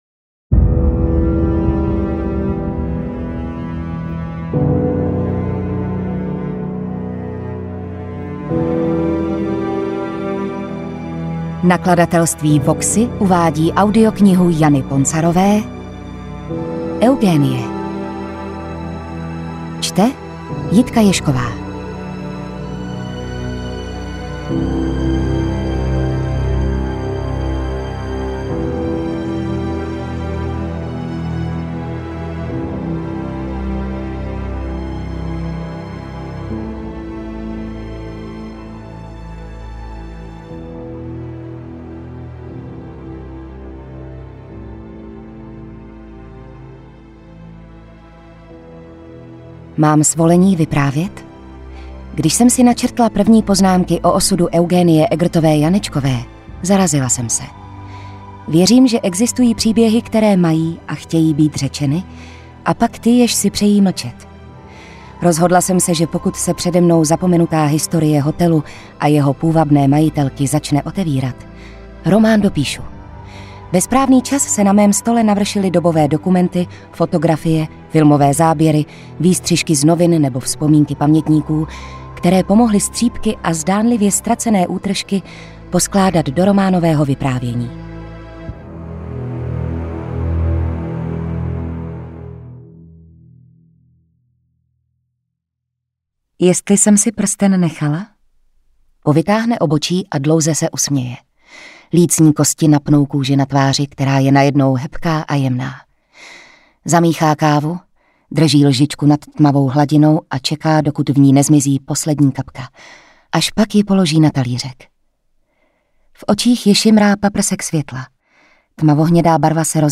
Interpret:  Jitka Ježková
AudioKniha ke stažení, 62 x mp3, délka 11 hod., velikost 601,4 MB, česky